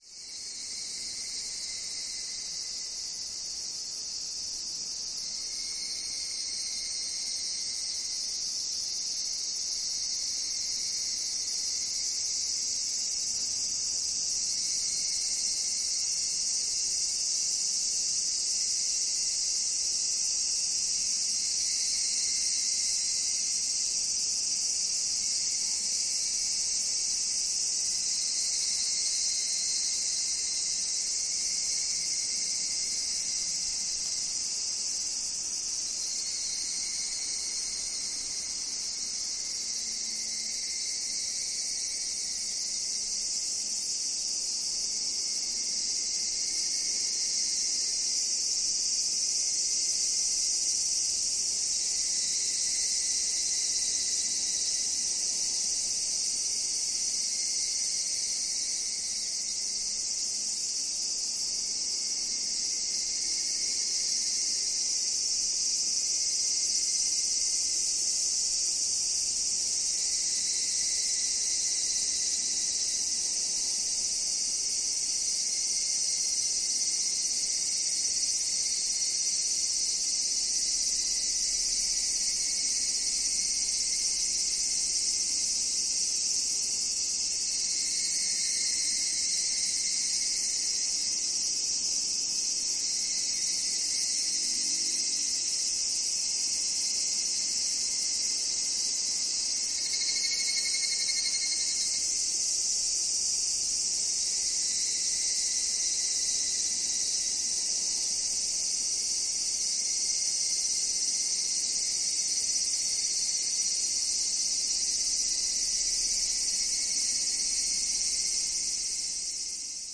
Cicada Chirping.mp3